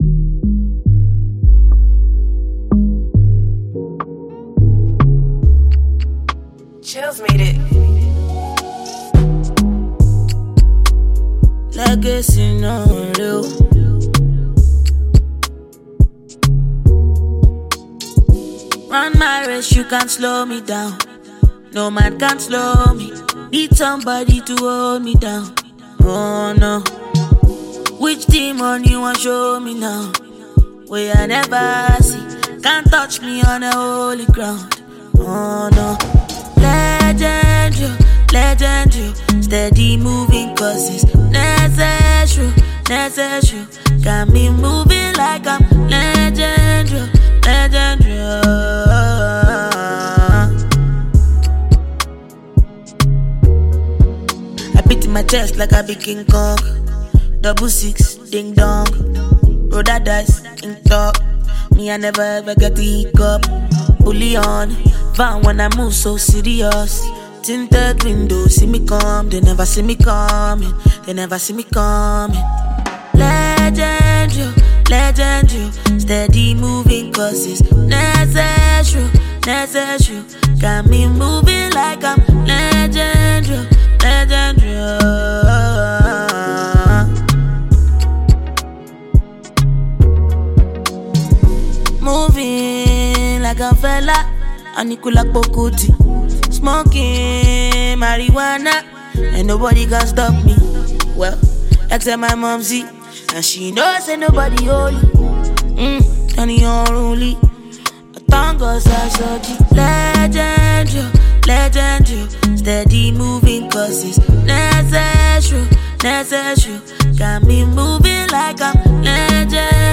is a mid-tempo record